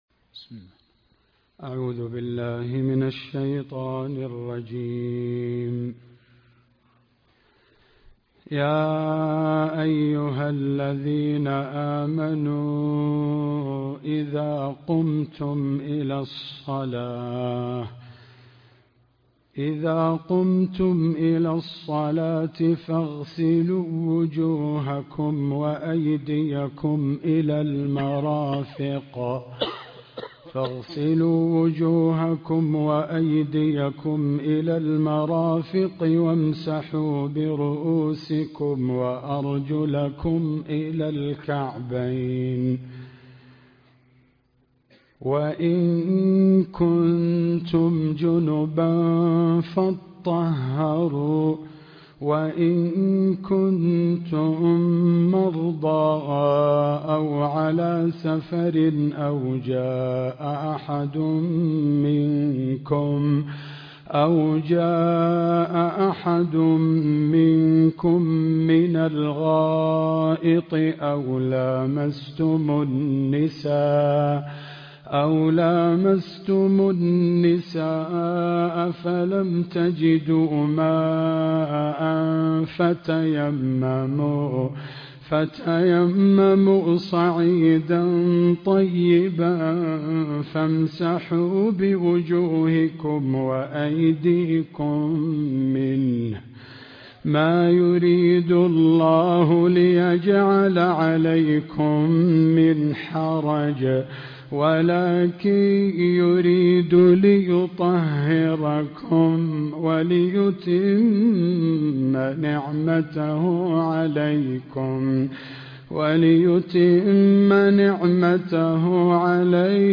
درس الطائف في آيات الأحكام